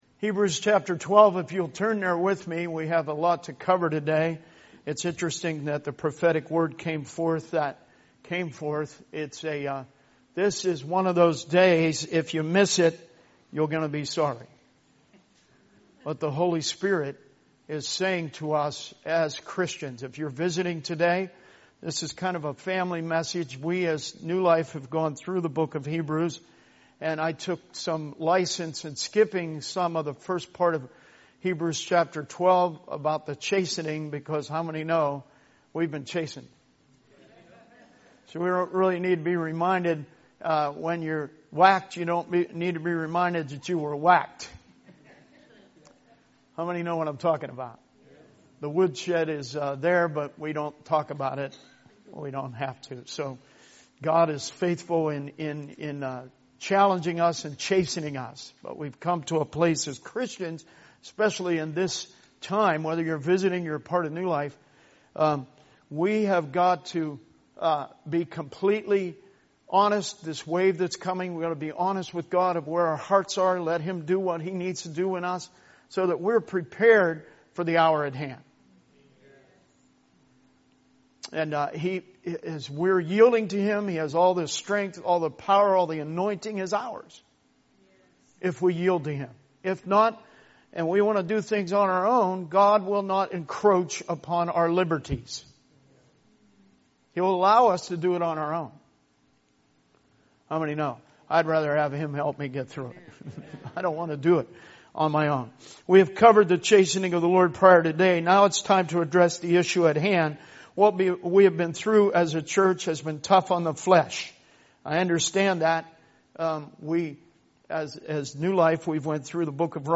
Sermon messages available online.
Service Type: Sunday Teaching